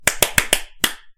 快速拍手
描述：我的手拍弗拉门戈风格（有点）
Tag: 拍手 鼓掌 敲击